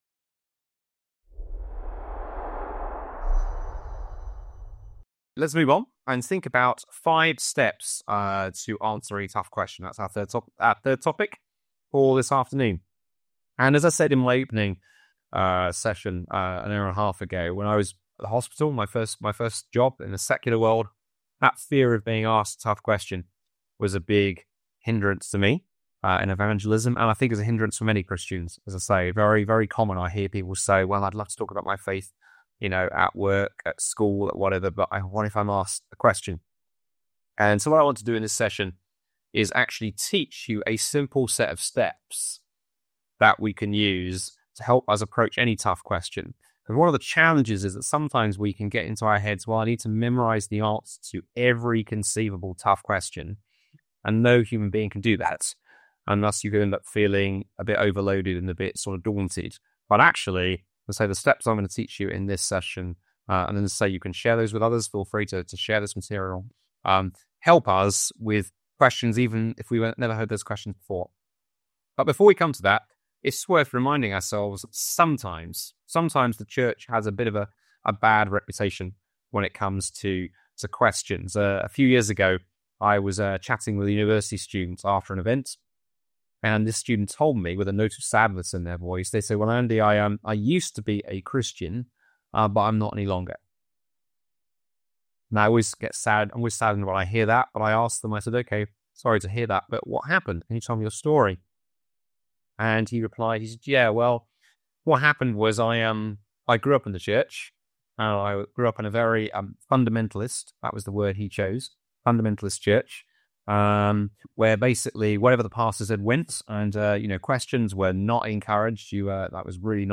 Master Class